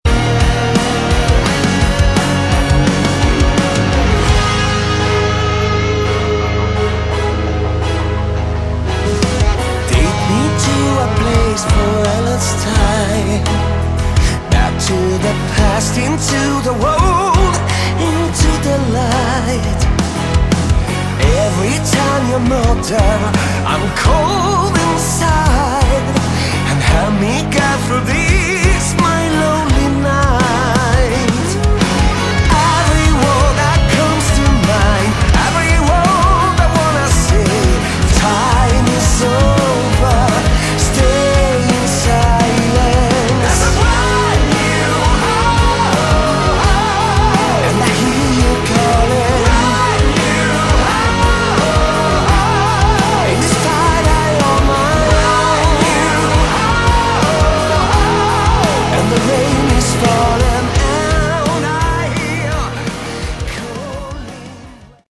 Category: Melodic Metal
lead vocals
guitars, keyboards
bass
drums